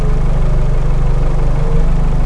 focussvt_idle.wav